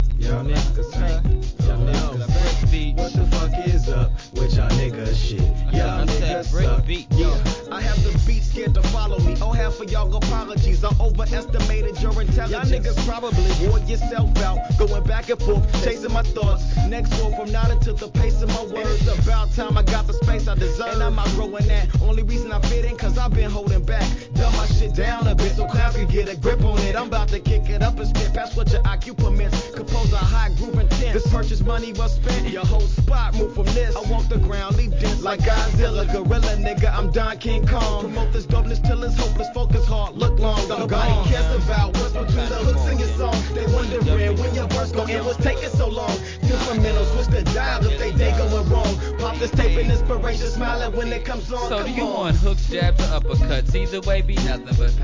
HIP HOP/R&B
ソウルフルなサウンドメイクの2006年1stアルバム!!!